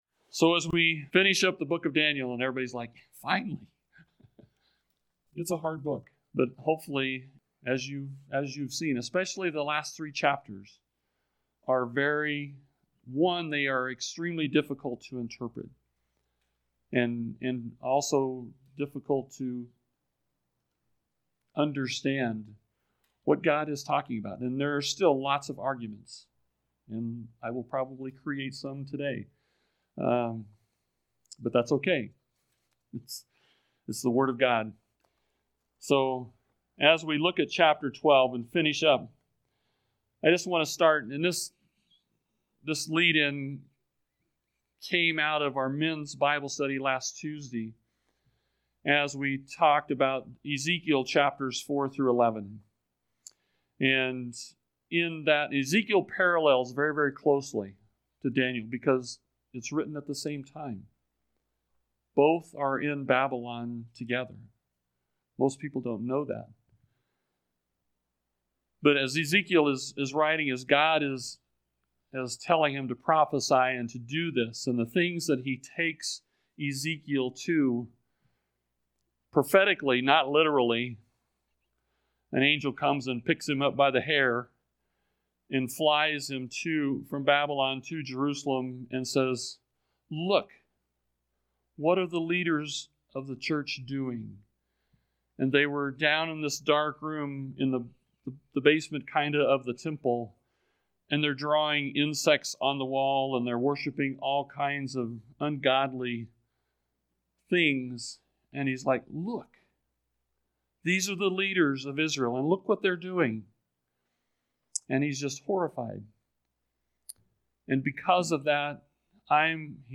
Recent Message